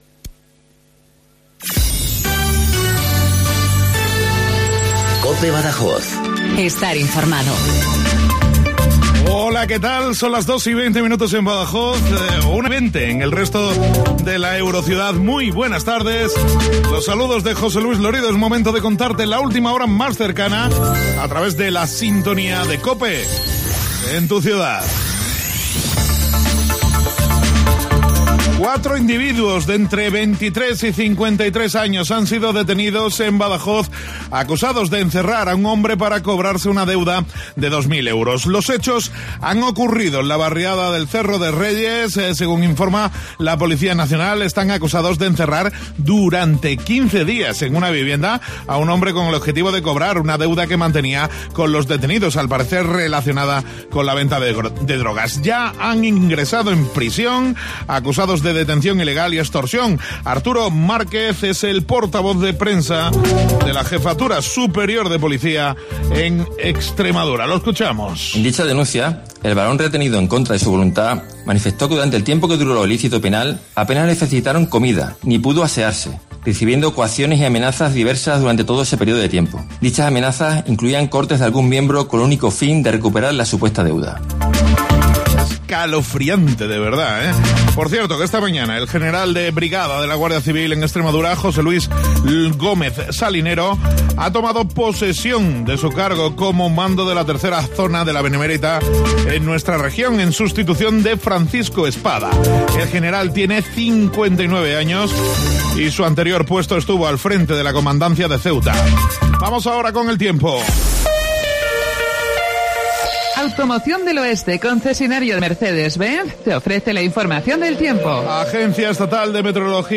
INFORMATIVO LOCAL BADAJOZ 1420